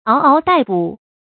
注音：ㄠˊ ㄠˊ ㄉㄞˋ ㄅㄨˇ
嗷嗷待哺的讀法